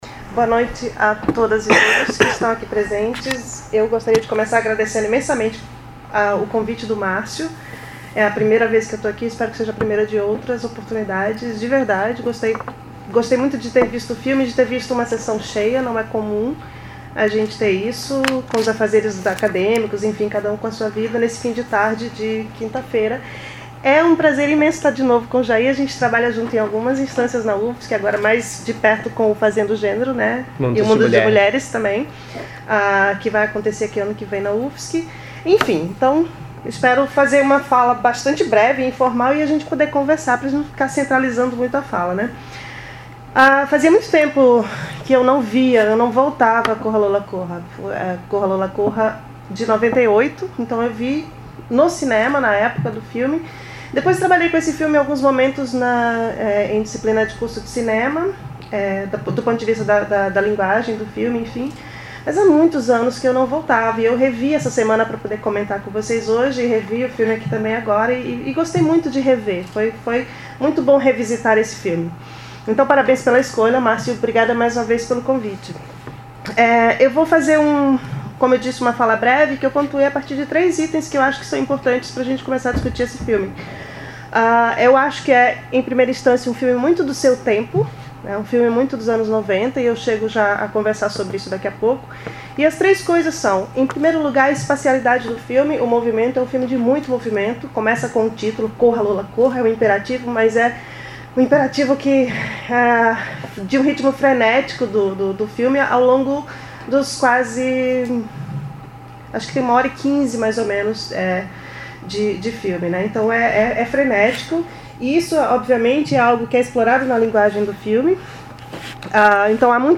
Comentários do filme "Corra, Lola, corra"